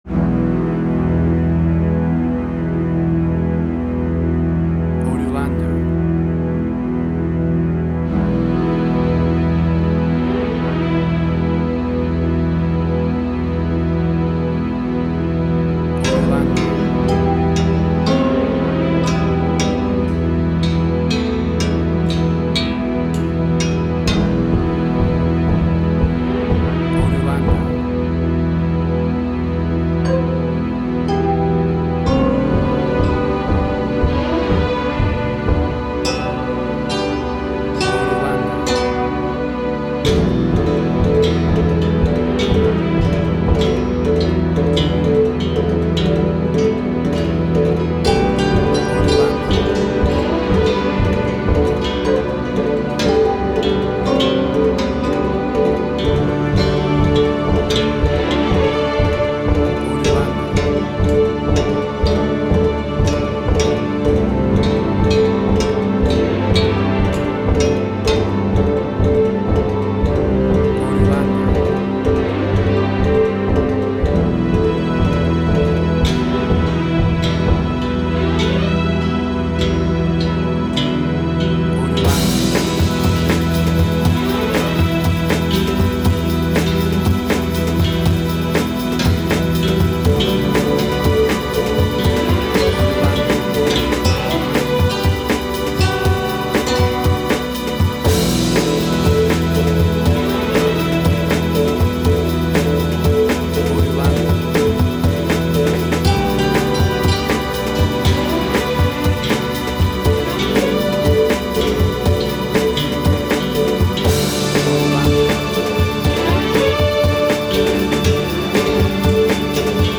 WAV Sample Rate: 16-Bit stereo, 44.1 kHz
Tempo (BPM): 120